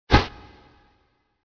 AMBIENTE TERROR Y FLASH CAMERA
Ambient sound effects
ambiente_terror_y_flash_camera.mp3